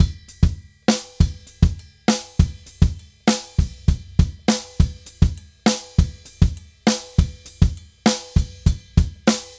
Drums_Baion 100_4.wav